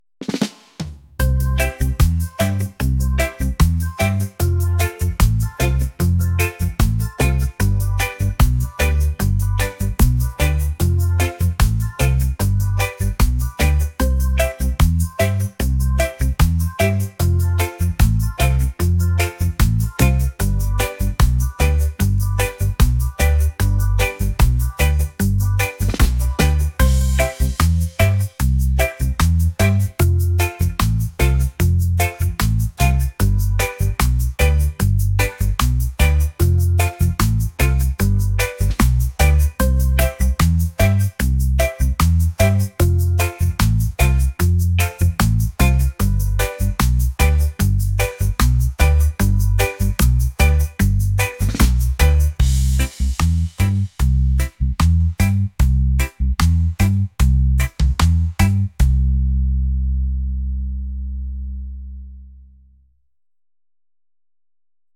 reggae | lofi & chill beats | retro